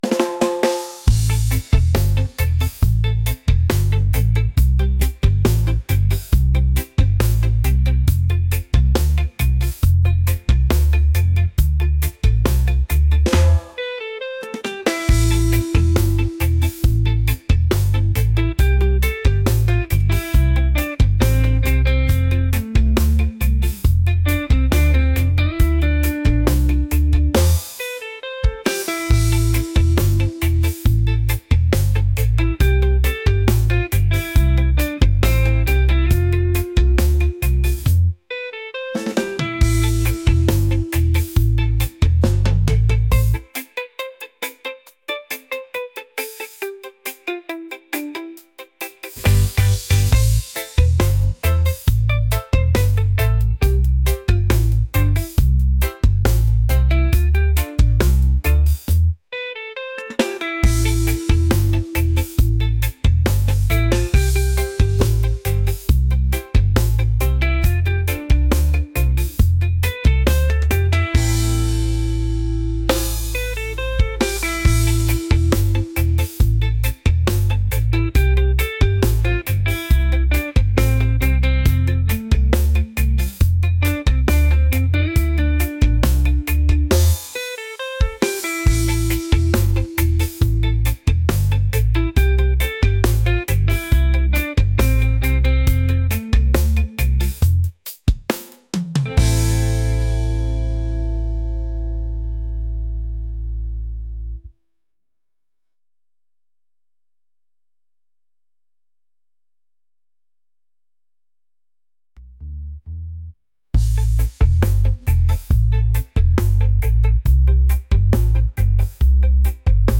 upbeat | reggae | fusion